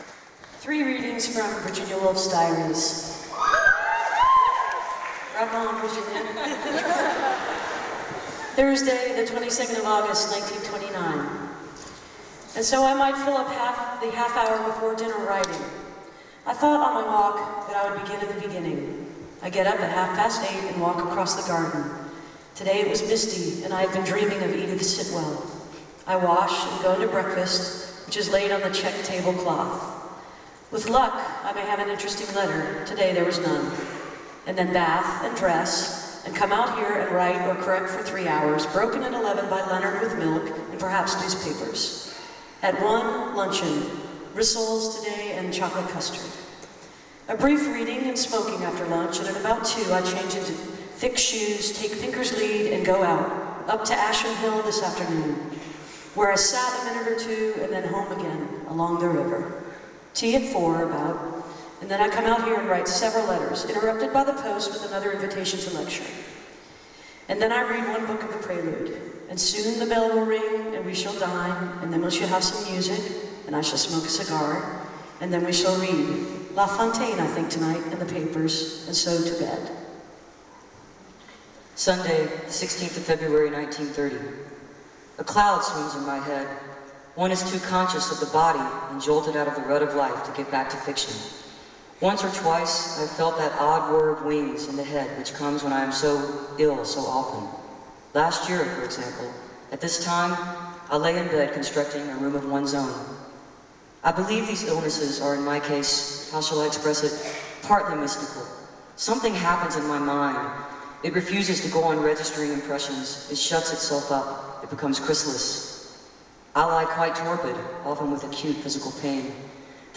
lifeblood: bootlegs: 2002-08-03: union chapel - london, england
03. reading from virginia woolf's diary - emily, amy, and sarah waters (3:55)